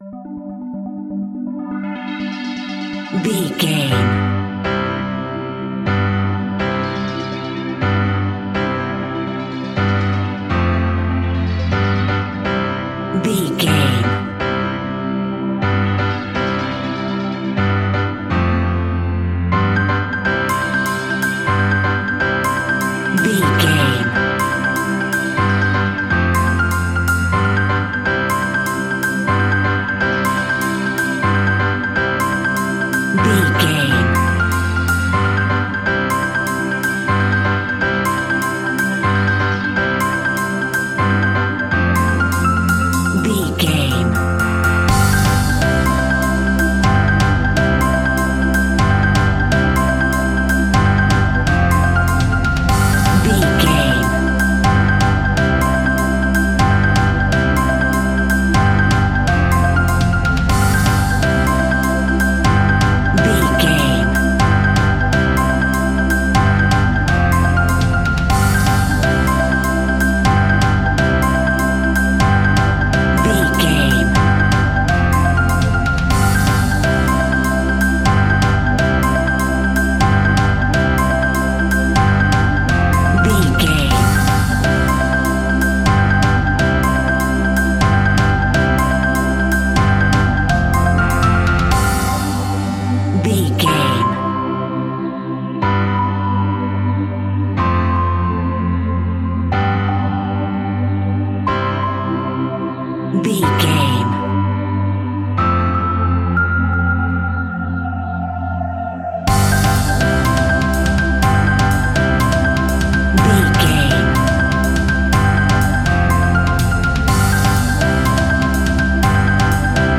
Mixolydian
pop rock
indie pop
energetic
uplifting
catchy
upbeat
acoustic guitar
electric guitar
drums
piano
organ
bass guitar